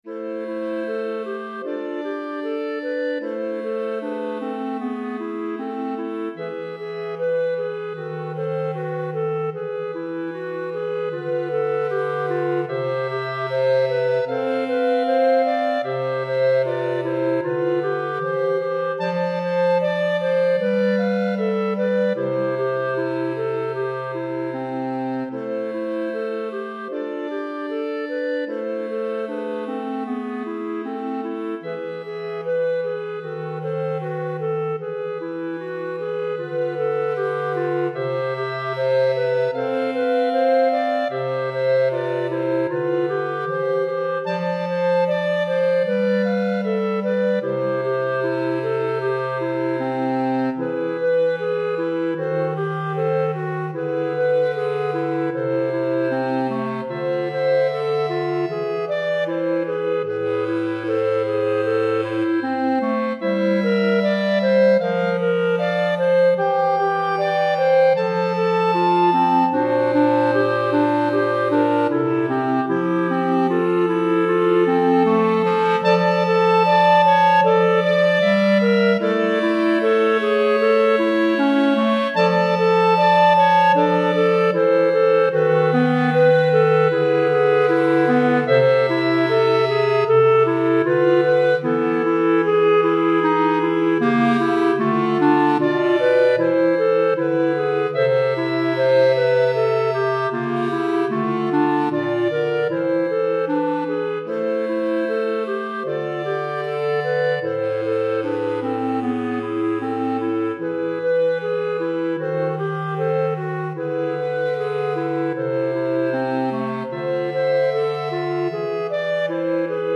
3 Clarinettes Sib et Clarinette Basse